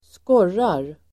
Ladda ner uttalet
Uttal: [²sk'år:ar]